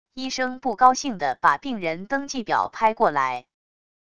医生不高兴的把病人登记表拍过来wav音频